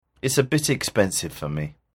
（ネイティブ音声付）